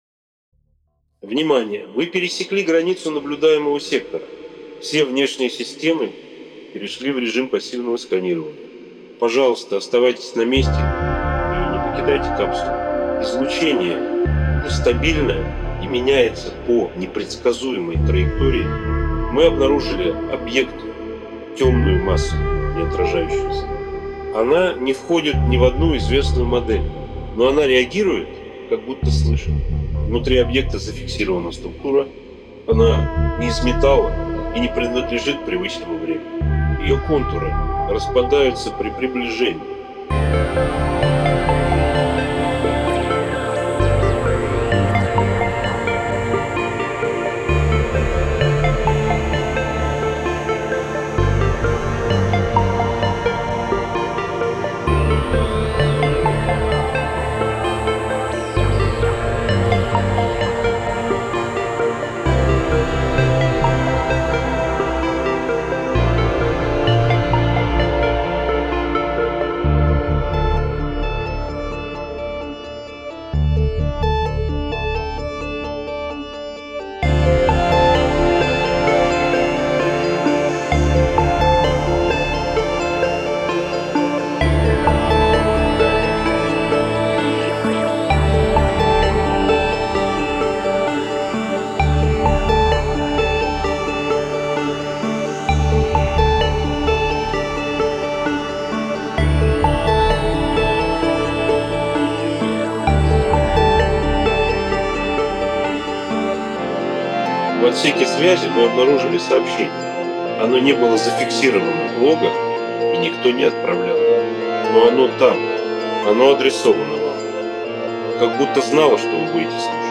Rmif Ti-5 (демо версия) и Gryphon для падов и подкладов. Emibas для баса и лидирующего инструмента.